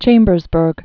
(chāmbərz-bûrg)